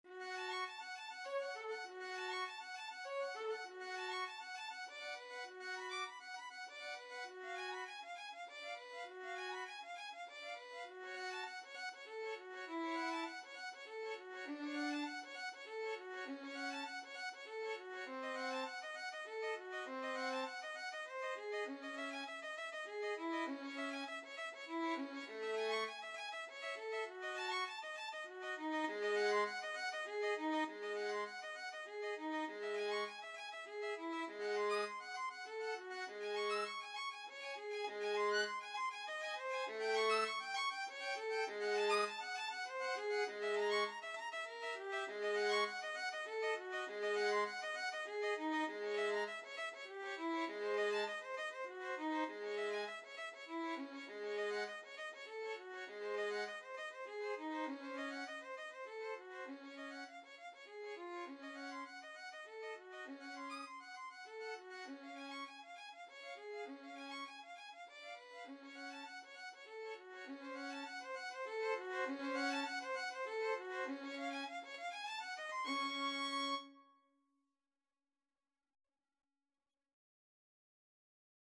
Violin 1Violin 2
3/4 (View more 3/4 Music)
Con moto, leggieramente
Classical (View more Classical Violin Duet Music)